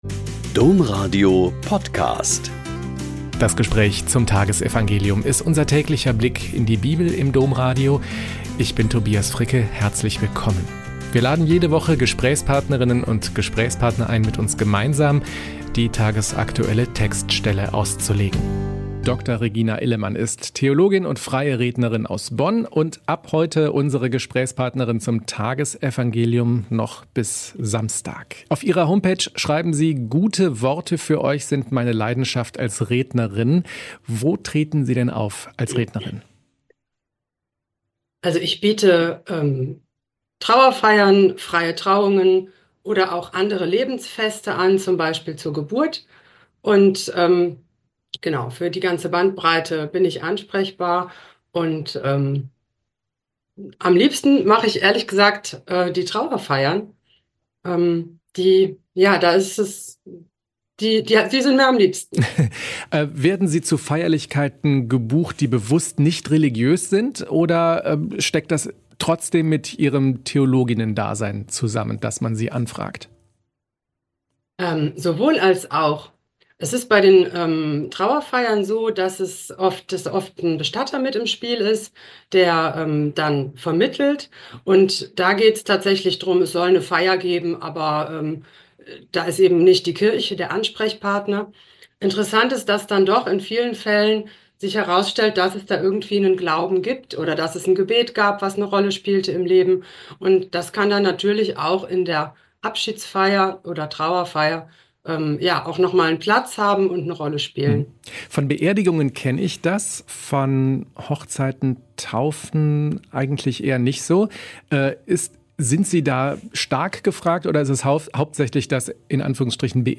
Mt 5, 43-48 - Gespräch